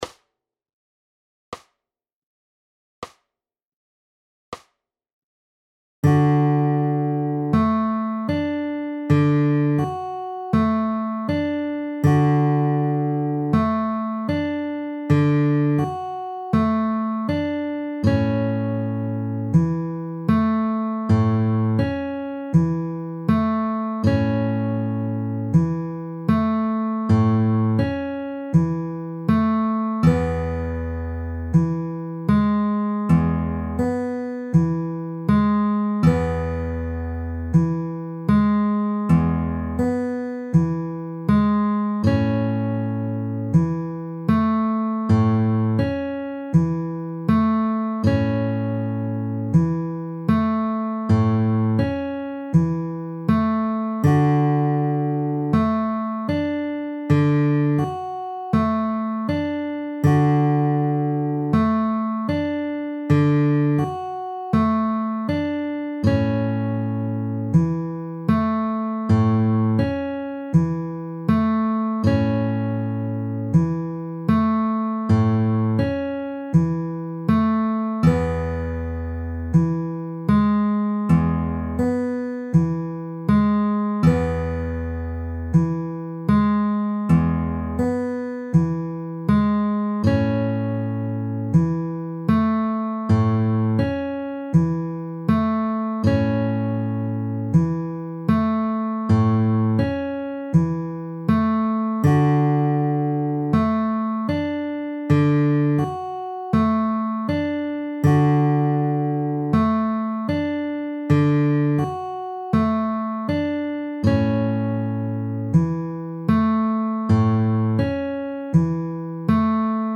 Fingerstyle, Travis Picking, Pinch play-alongs - Guitar Lessons in Myrtle Beach, SC
D-A-E-A-pinch-at-40-bpm.mp3